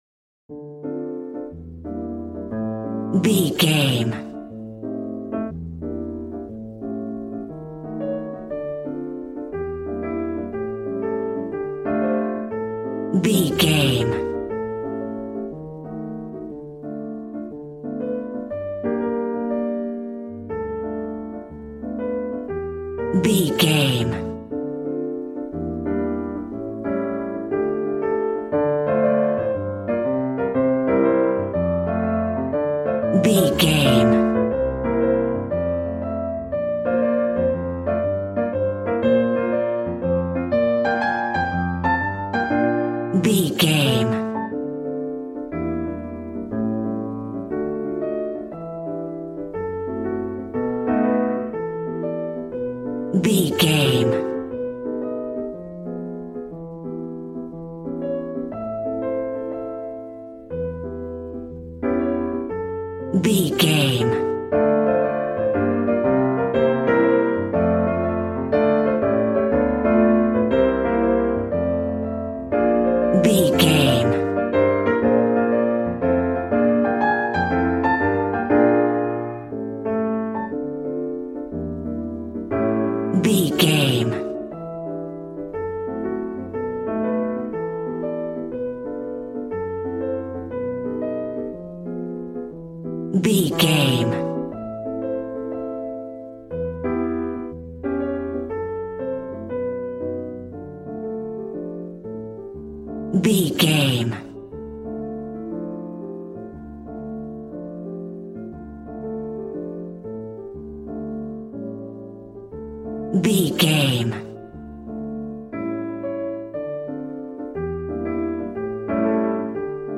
Smooth jazz piano mixed with jazz bass and cool jazz drums.
Aeolian/Minor
D
smooth
piano
drums